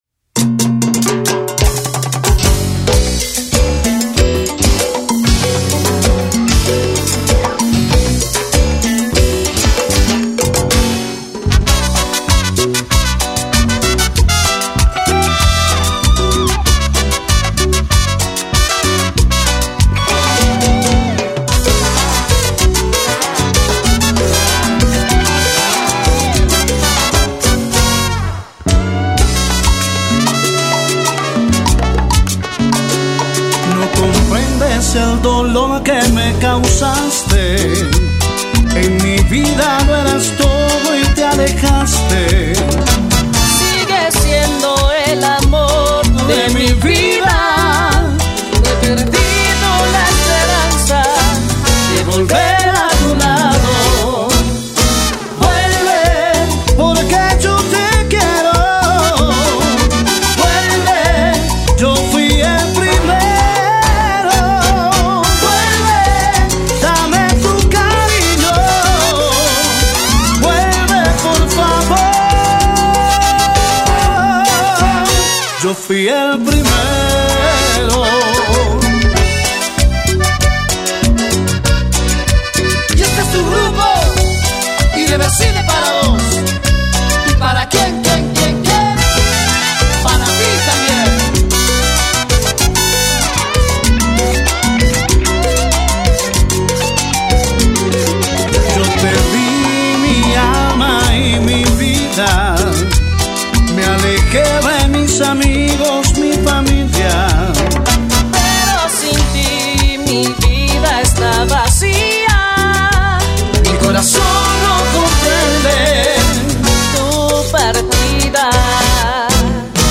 Cumbia Latina